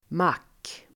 Uttal: [mak:]